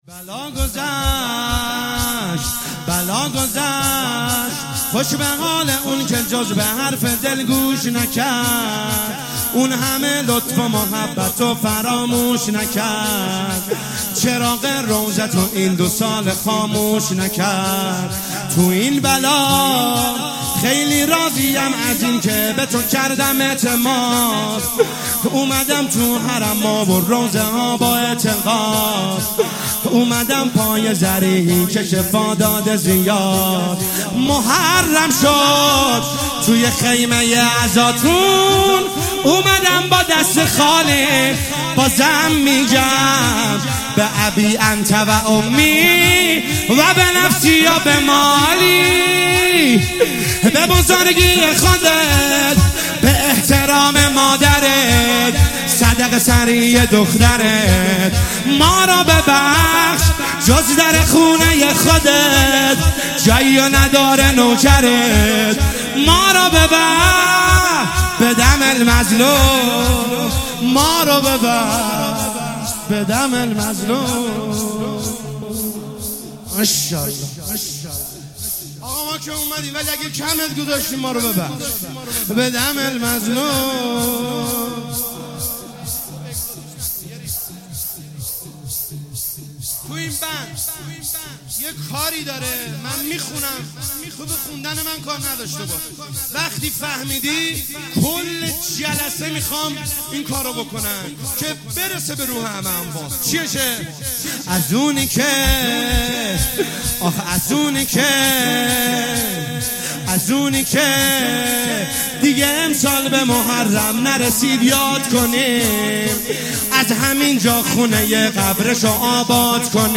محرم1401شب اول - شور - بلاء گذشت